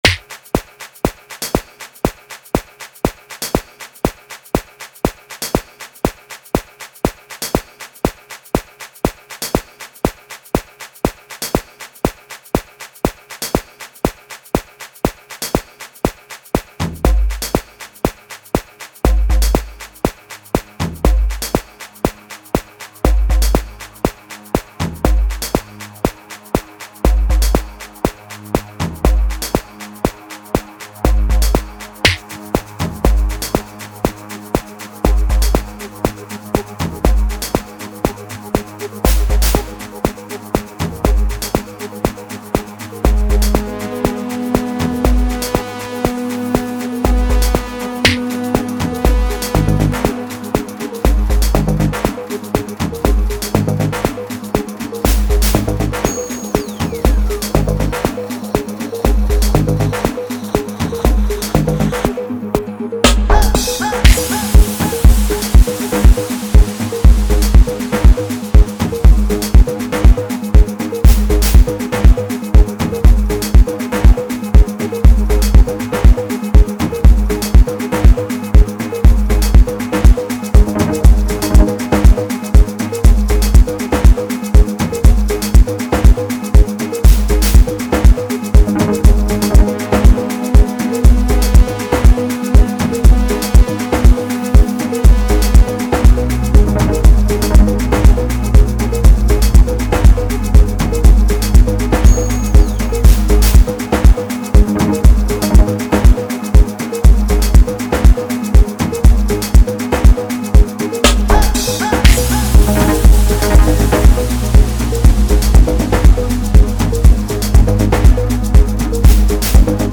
S African talented House music producer